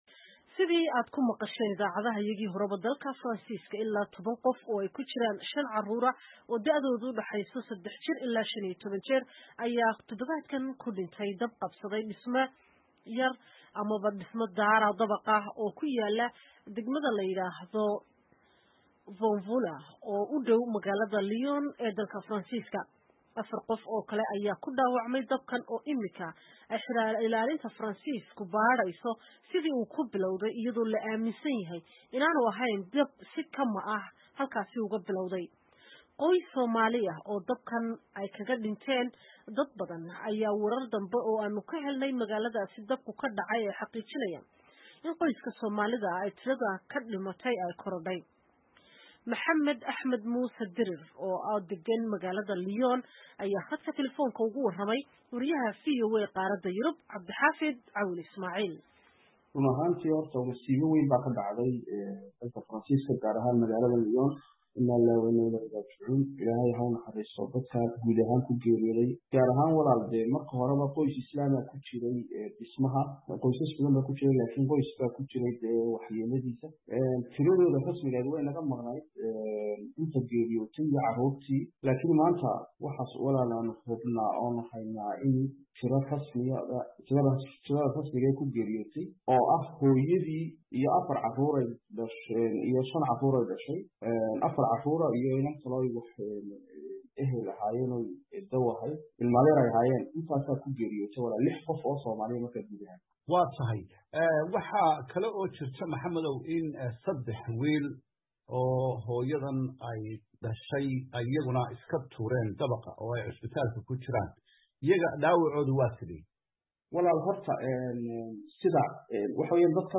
ayaa wararka ugu dambeeyey ee khasaaraha dabkaas ka waraystay